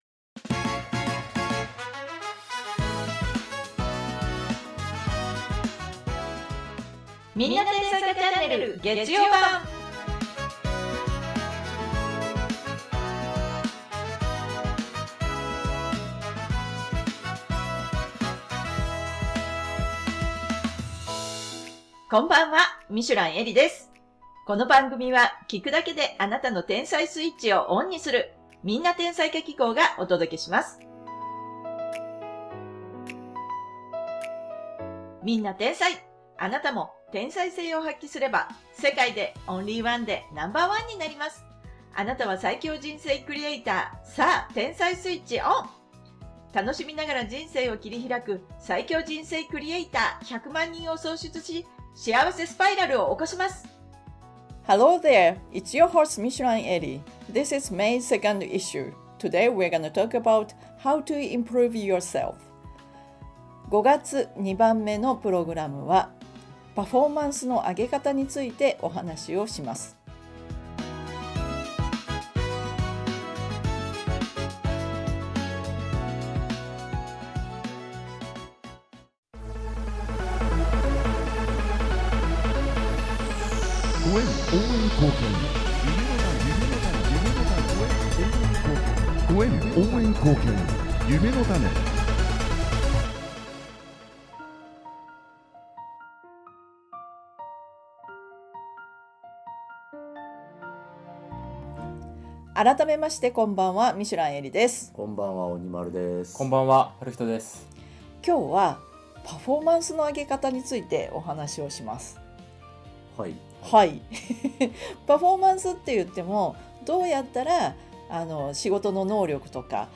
成果を出す人は「実力」だけでなく、段取り力・正しい方向性・そしてある“視点”を持っている。成長を加速させたい人へ贈るラジオ回。